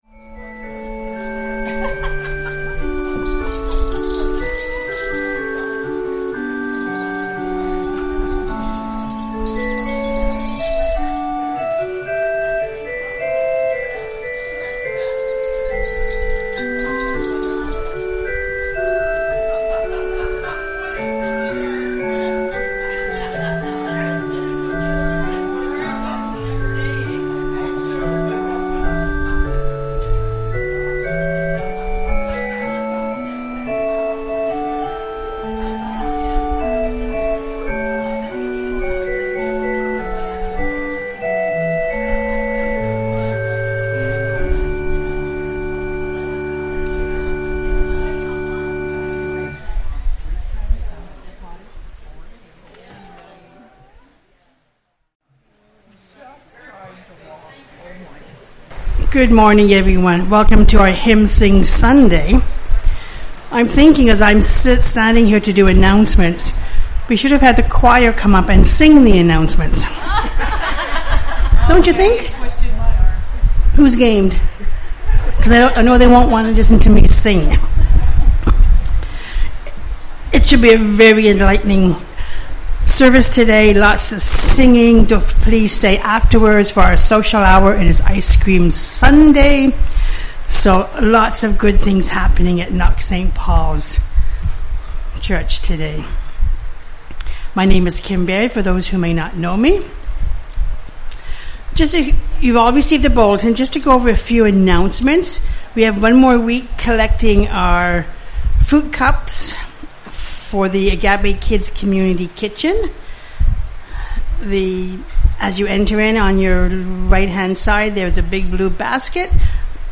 Hymn Sing Sunday 2024